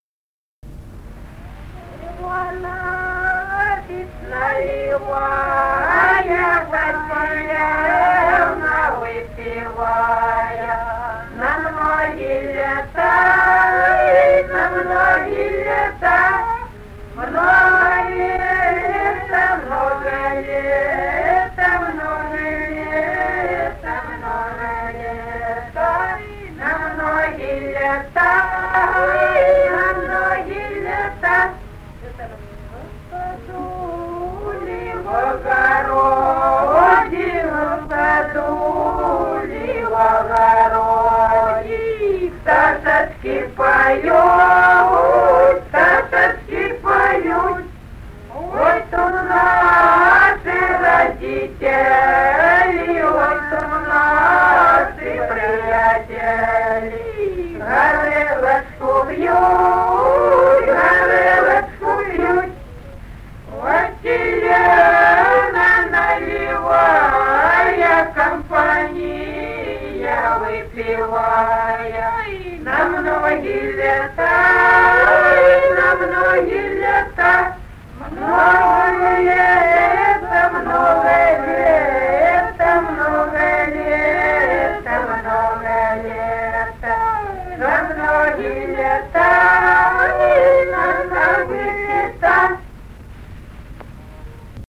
Народные песни Стародубского района «Иванович наливая», застольная «банкетная» песня.
с. Курковичи.